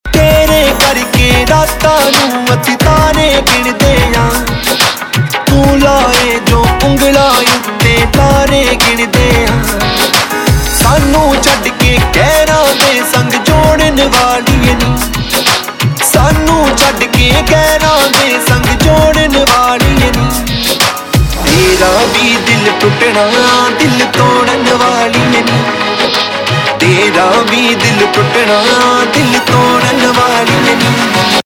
HomeRingtones Mp3 > Punjabi Mp3 Tone